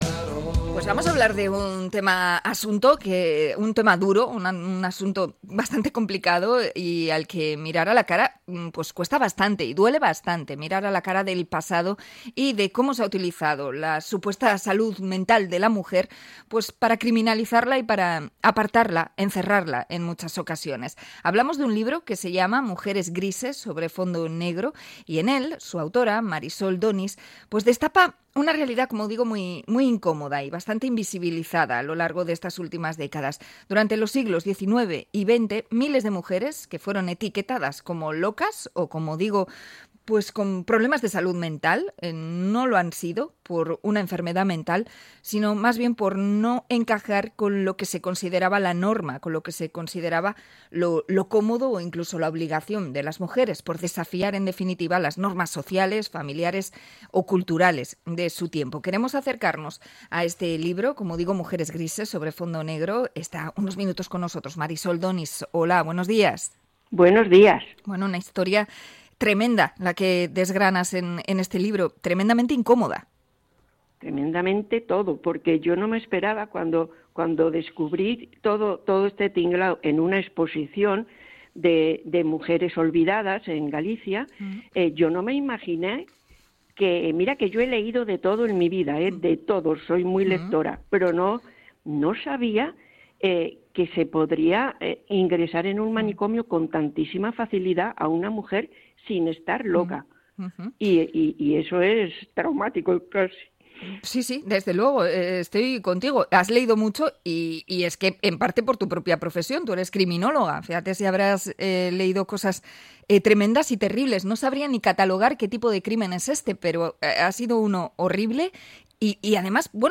Entrevista por el libro Mujeres Grises sobre Fondo Negro